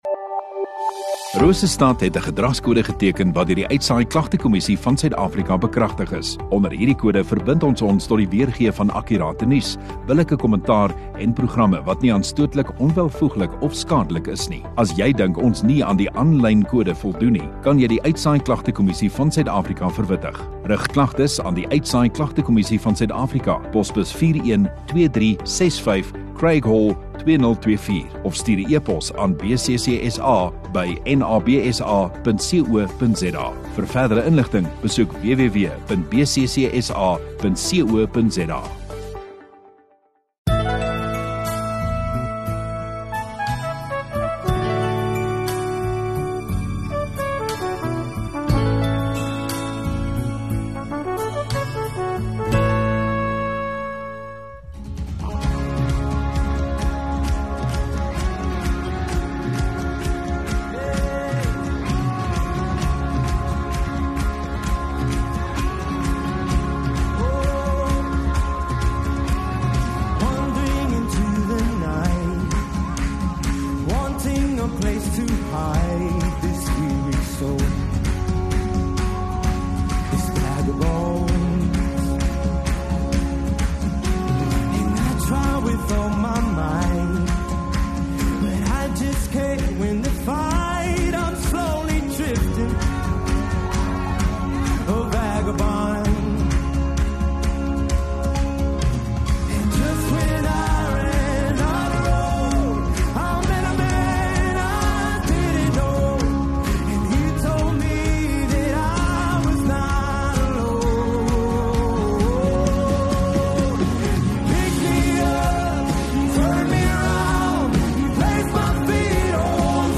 16 Feb Sondagoggend Erediens